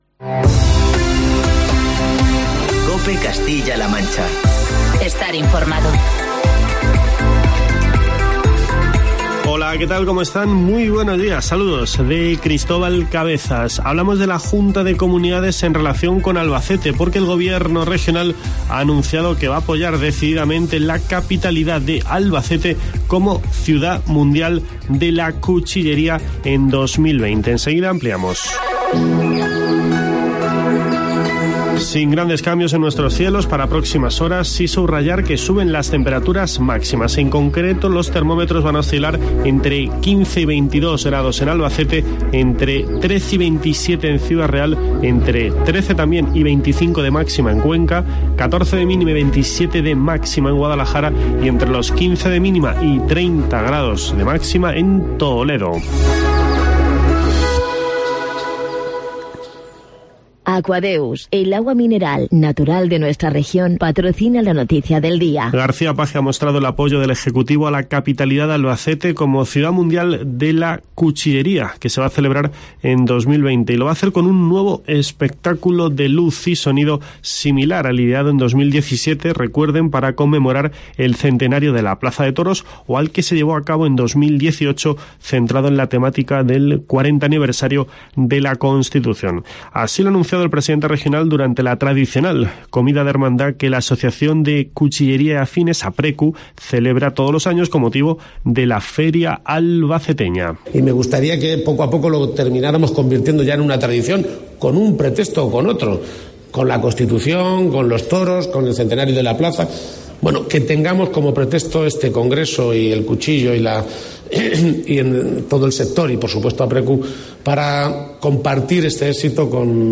Escucha las palabras del consejero de Agricultura, Francisco Martínez Arroyo, en el informativo matinal de COPE Castilla-La Mancha